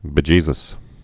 (bĭ-jēzəs)